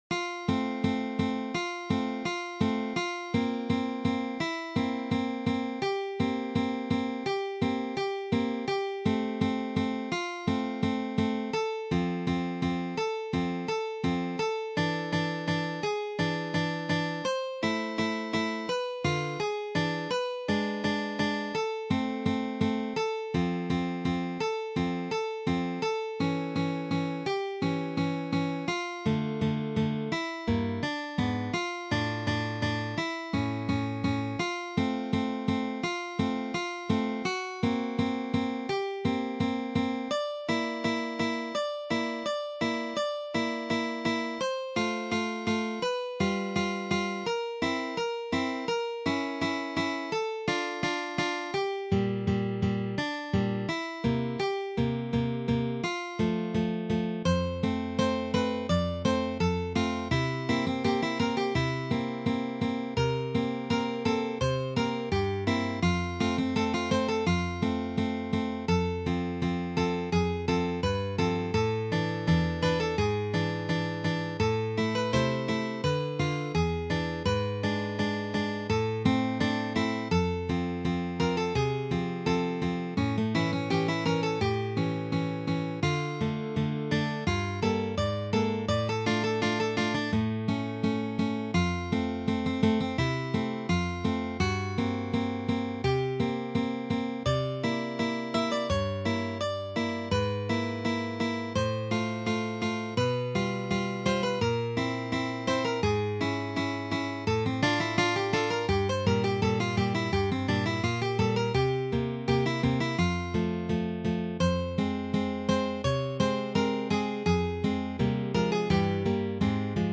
for three guitars plus a solo guitar
This is from the Classical period.